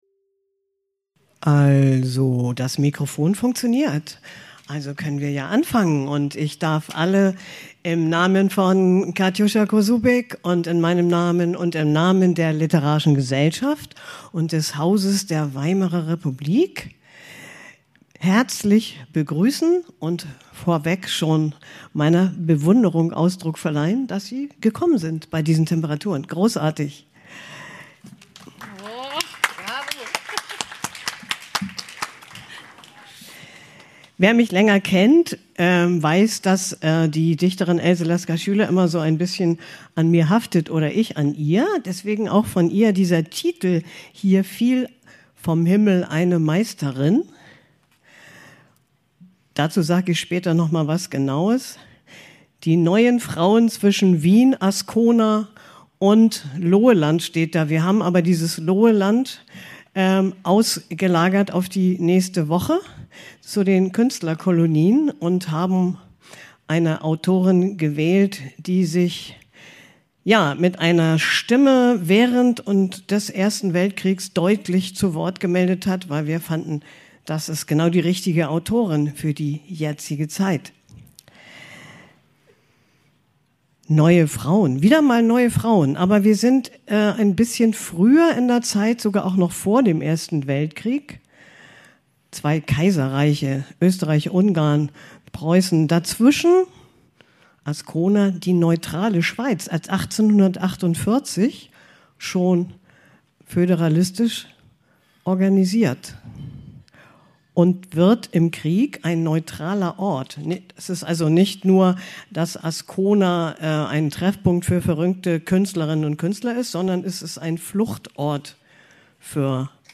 Visionäre Orte, Künstlerinnen und Lebensentwürfe der frühen Moderne" Aufzeichnung einer Open-air-Lesung im Weimarer Künstlergarten vom 2.7.2025 Mehr